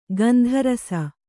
♪ gandha rasa